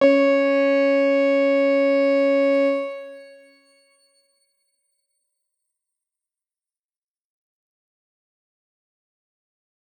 X_Grain-C#4-pp.wav